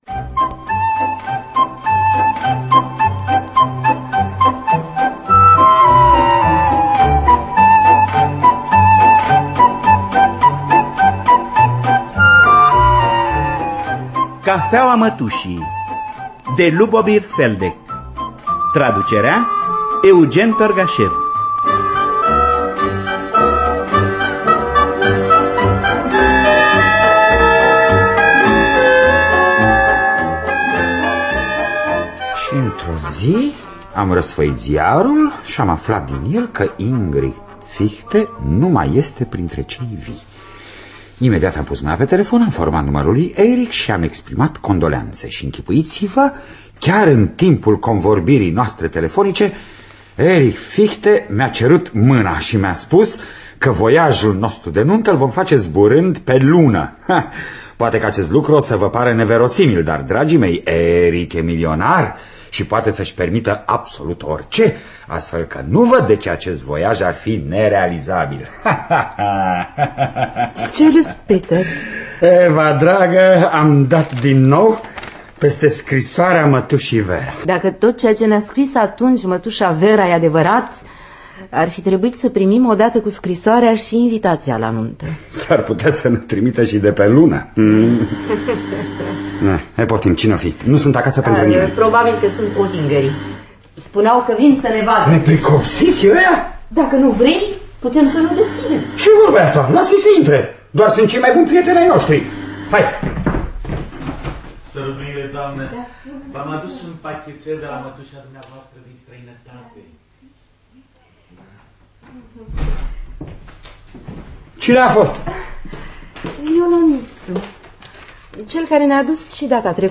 "Cafeaua mătușii" de Ľubomír Feldek. Adaptarea radiofonică